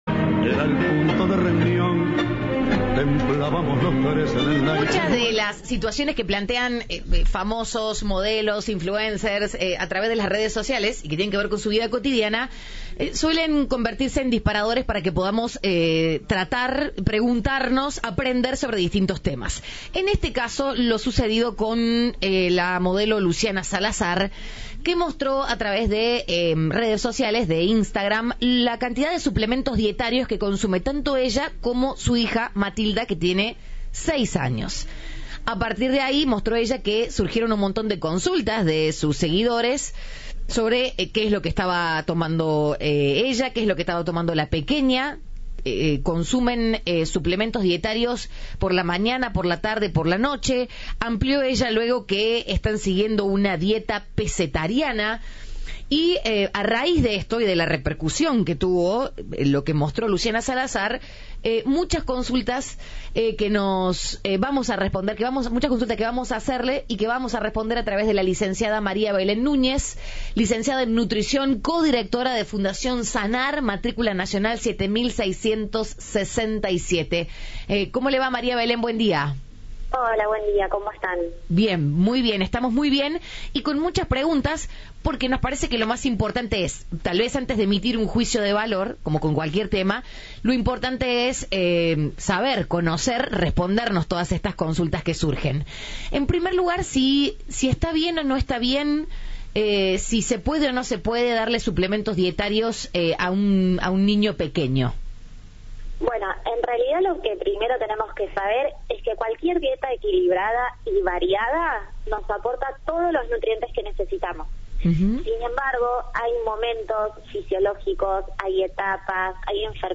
Celia Arena, diputada provincial de Santa Fe, habló en Cadena 3 Rosario.